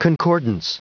Prononciation du mot concordance en anglais (fichier audio)
Prononciation du mot : concordance